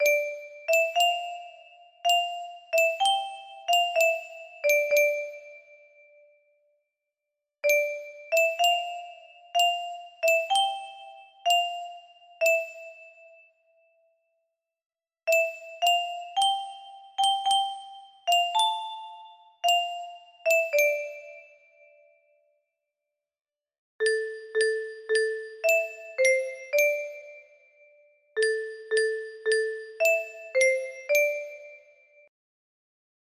music box melody
Lullaby